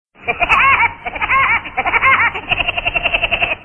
/64kbps) Описание: Смех Вуди дятла ID 26520 Просмотрен 20116 раз Скачан 10456 раз Скопируй ссылку и скачай Fget-ом в течение 1-2 дней!